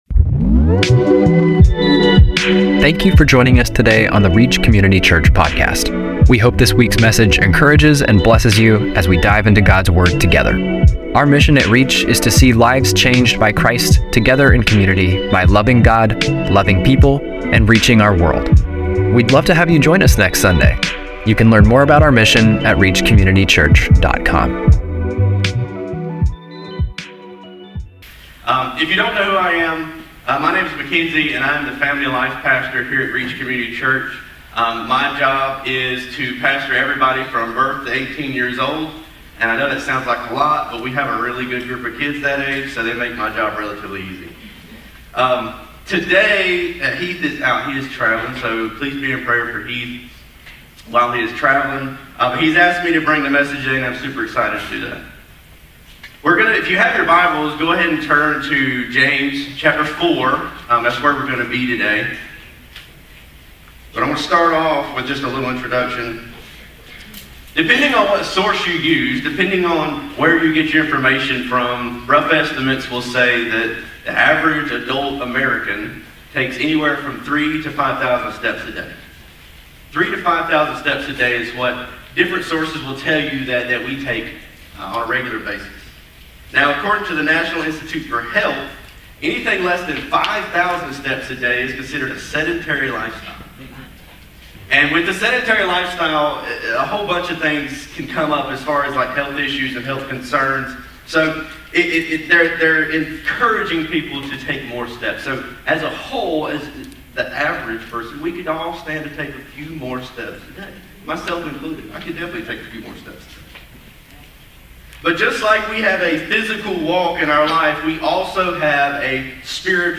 9-28-25-Sermon-.mp3